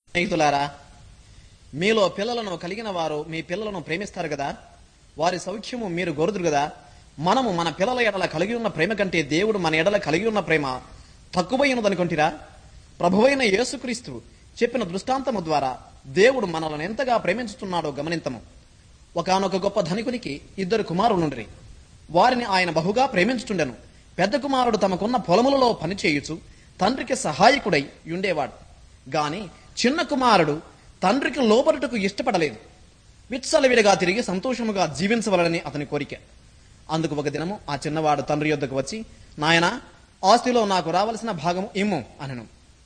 It sounds like the guy in the recording is speaking very quickly.